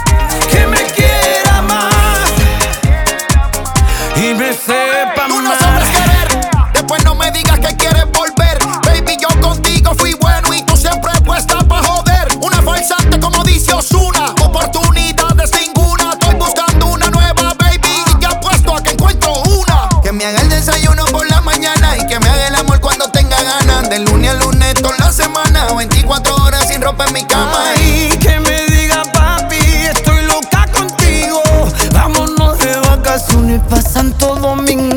Música tropical, Latin